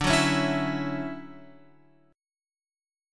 D Major 9th